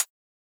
Index of /musicradar/retro-drum-machine-samples/Drums Hits/Raw
RDM_Raw_SY1-ClHat.wav